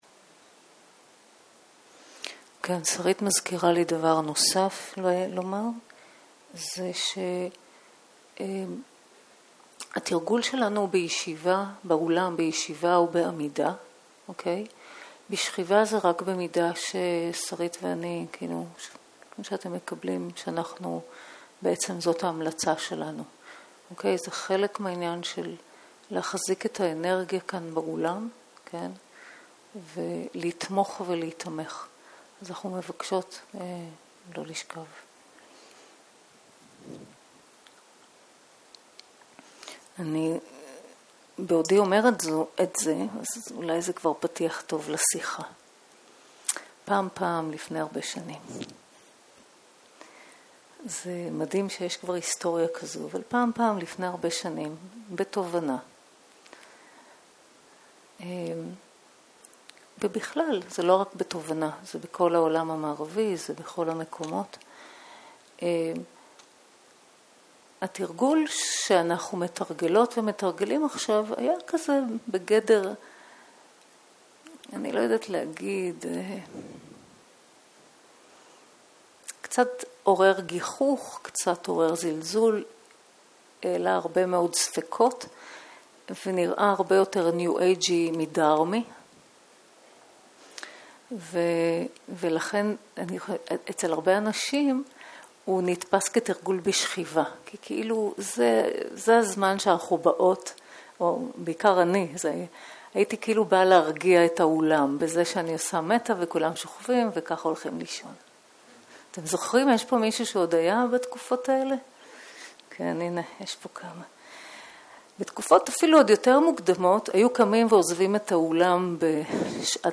ערב - שיחת דהרמה
סוג ההקלטה: שיחות דהרמה